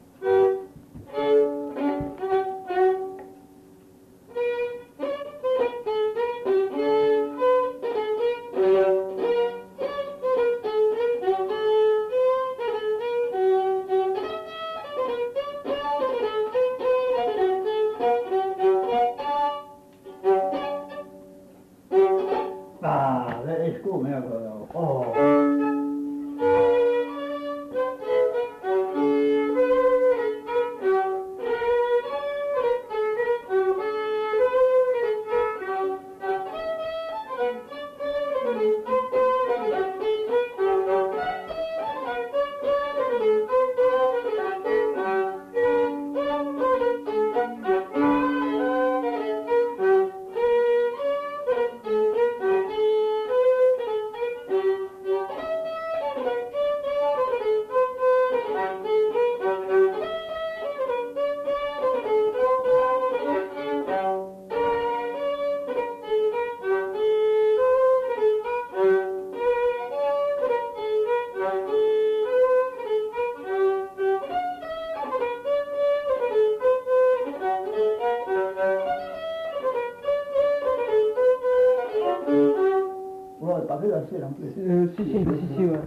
Lieu : Saint-Michel-de-Castelnau
Genre : morceau instrumental
Instrument de musique : violon
Danse : polka piquée